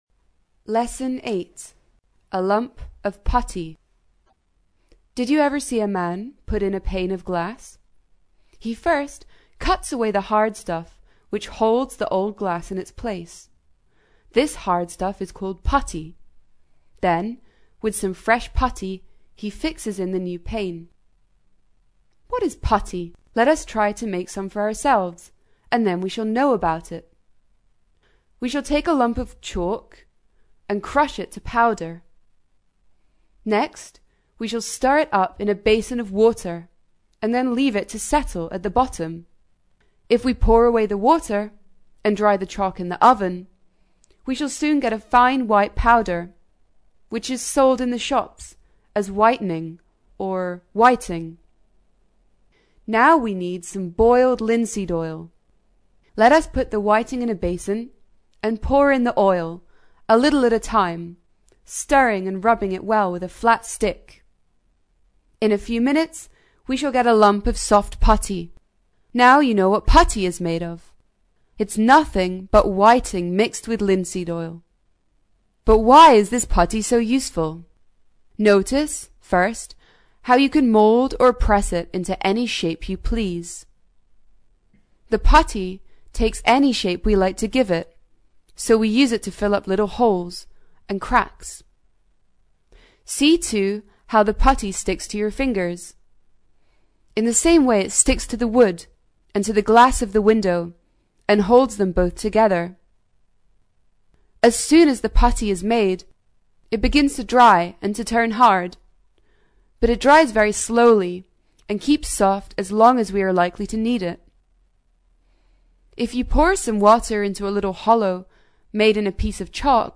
在线英语听力室英国学生科学读本 第8期:一块腻子的听力文件下载,《英国学生科学读本》讲述大自然中的动物、植物等广博的科学知识，犹如一部万物简史。在线英语听力室提供配套英文朗读与双语字幕，帮助读者全面提升英语阅读水平。